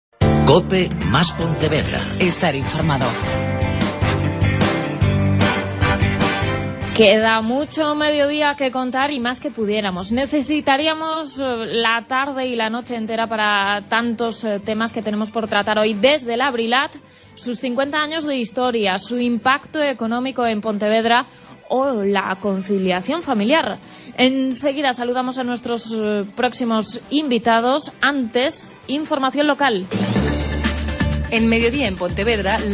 AUDIO: Hoy programa especial desde la BRILAT en Pontevedra